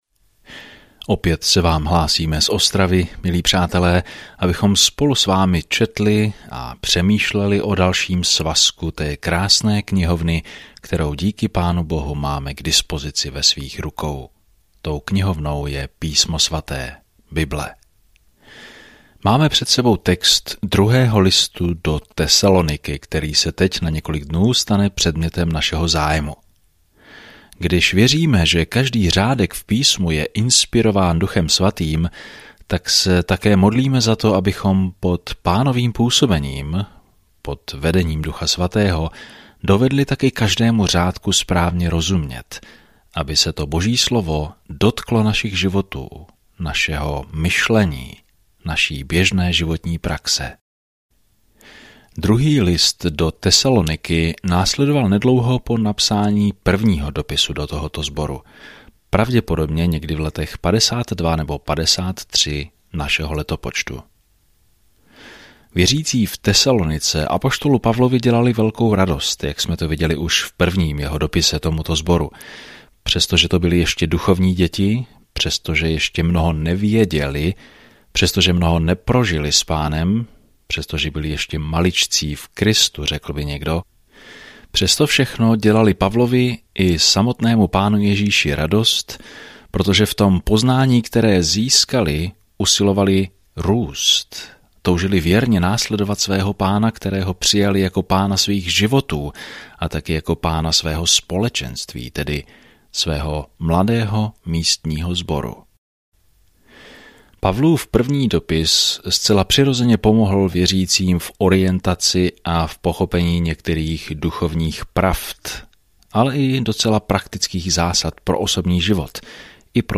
Denně procházejte 2 Tesaloničany a poslouchejte audiostudii a čtěte vybrané verše z Božího slova.